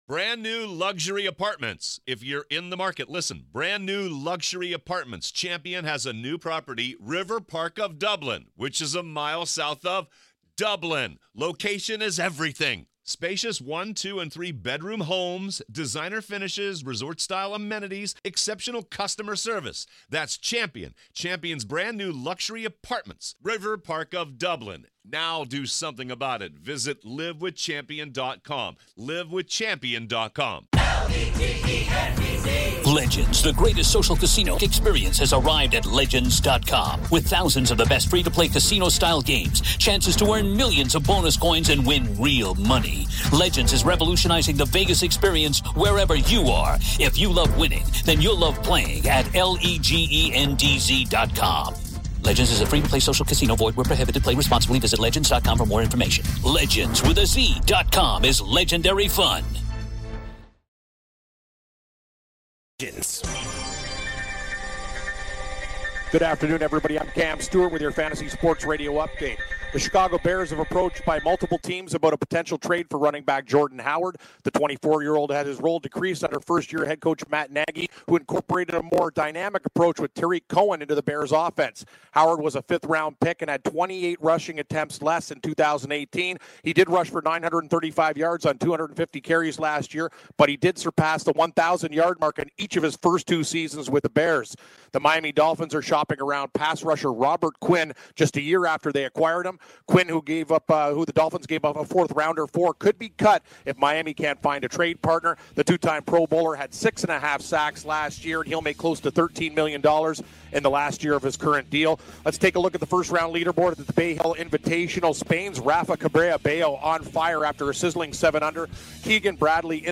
Two NBA Games Tonight, Antonio Brown Rumors, College Basketball Preview, Rick Barry Interview